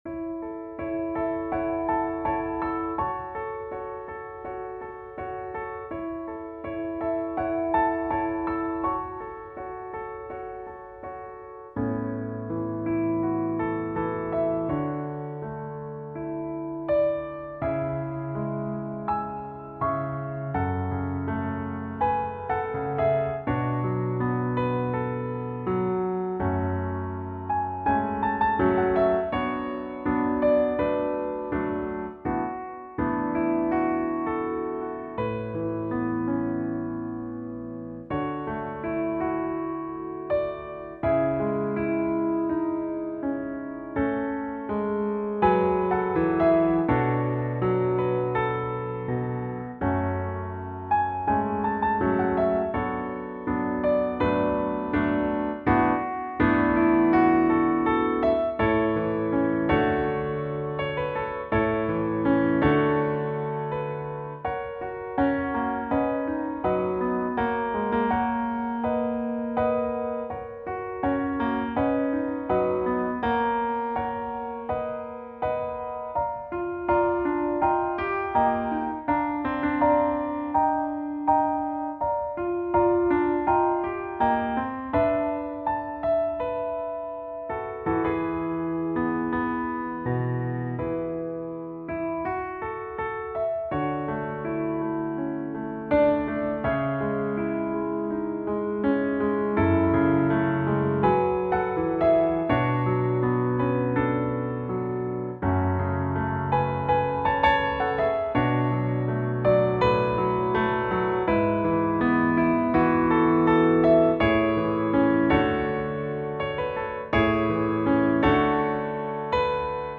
beautiful piano arrangement